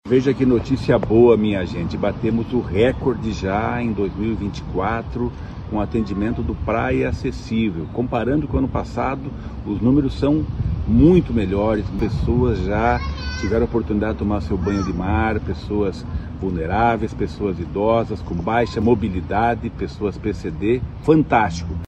Sonora do secretário do Desenvolvimento Social e Família, Rogério Carboni, sobre os atendimentos do programa Praia Acessível